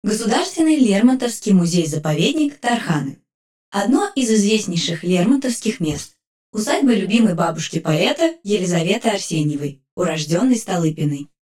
monument1_s-001_echo.ogg